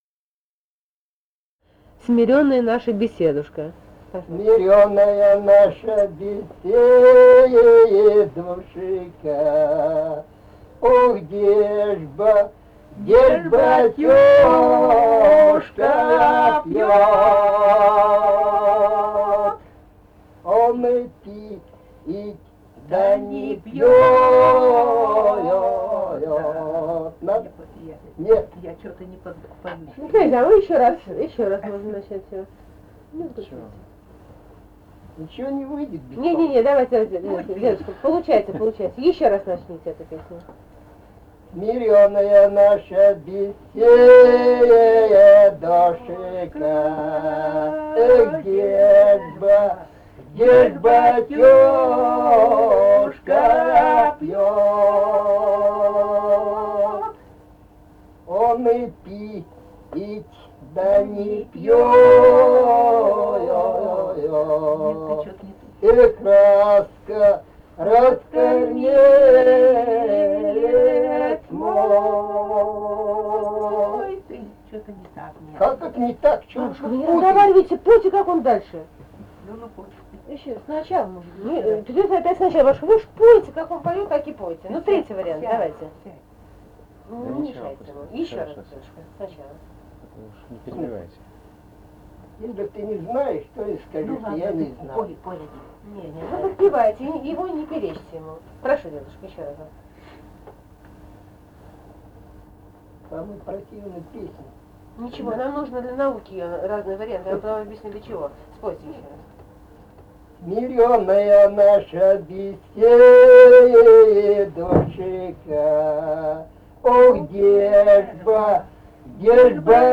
Этномузыкологические исследования и полевые материалы
«Смирённая наша беседушка» (лирическая).
Алтайский край, Заимка Борзовая (округ г. Барнаула), 1967 г. И1016-03